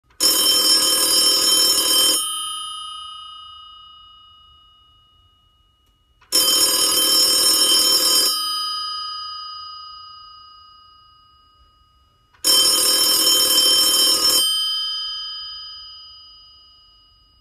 Tags: musical theater